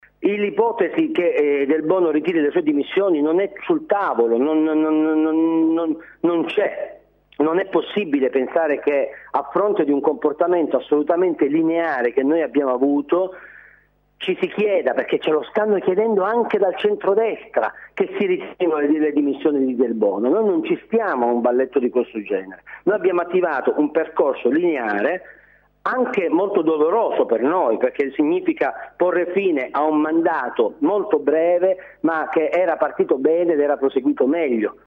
“E’ un balletto che non ci appartiene” – ribadisce Lo Giudice ai nostri microfoni